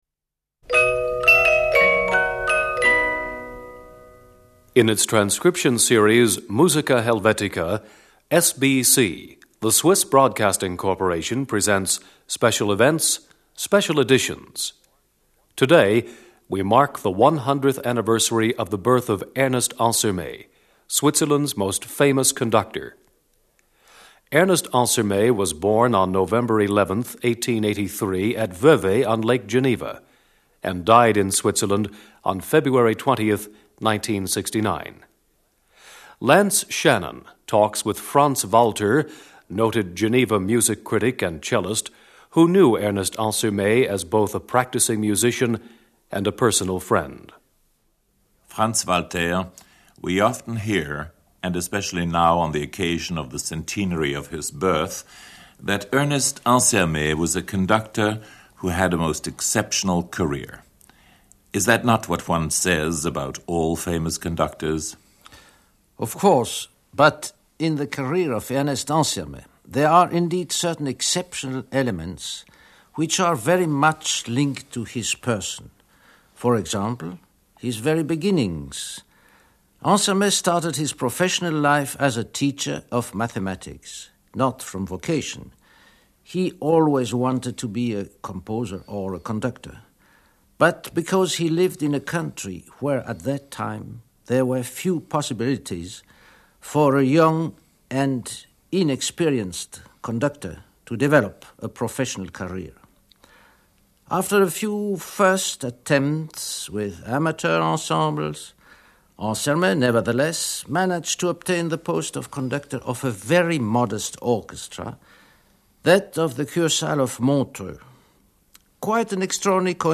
Programme Contents: 1. Ernest Ansermet speaks about Igor Stravinsky in Switzerland. 2.